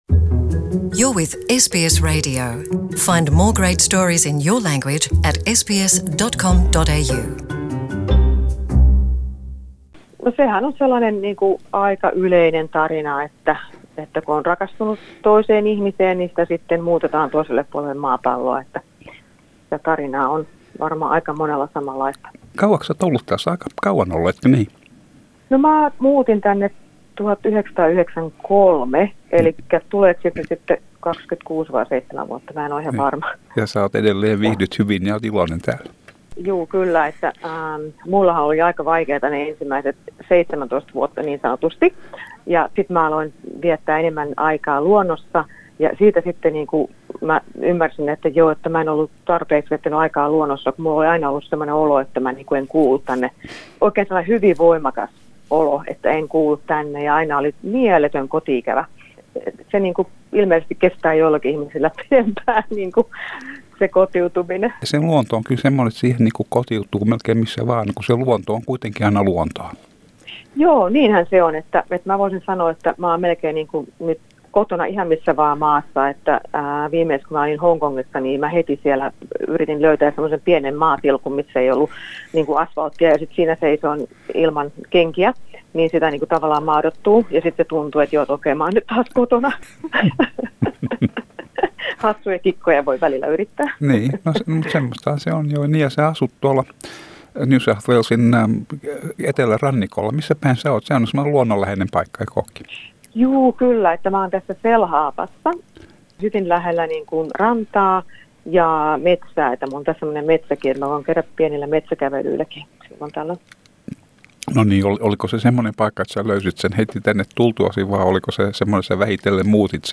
tässä haastattelussa hän kertoo miten hän tuli muuttaneeksi Australiaan